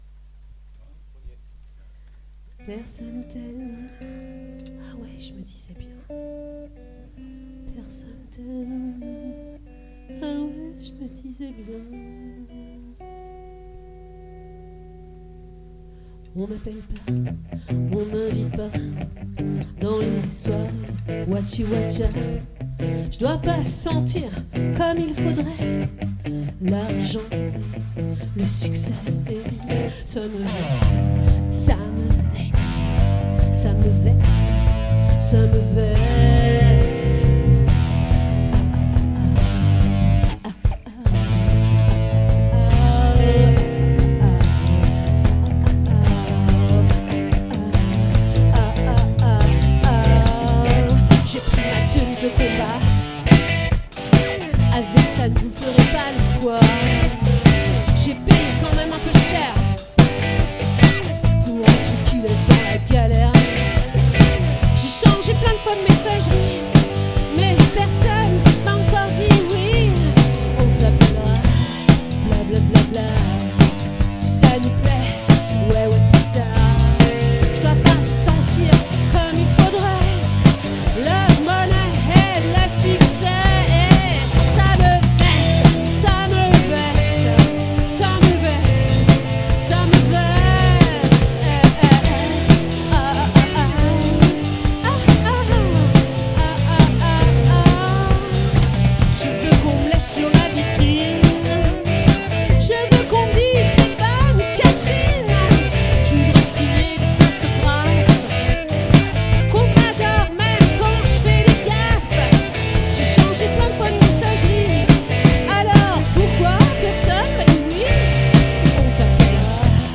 🏠 Accueil Repetitions Records_2023_04_19